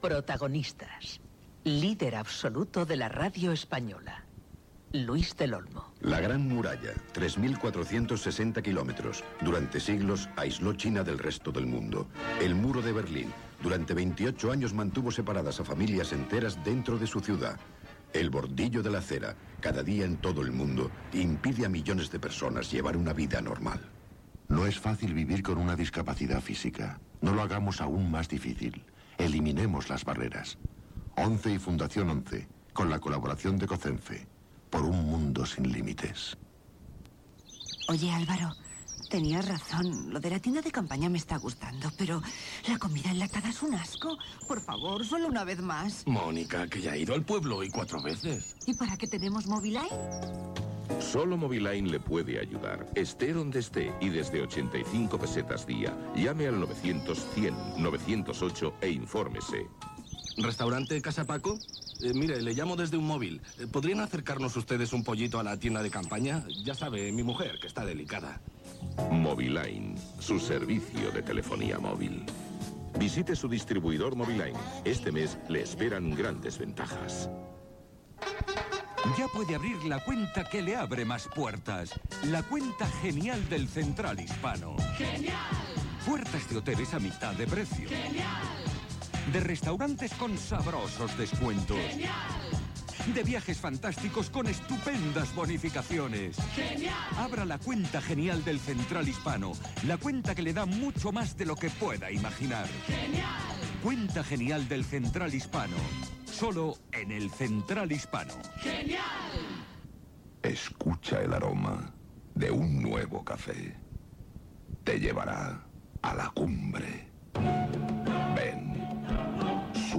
Gènere radiofònic Info-entreteniment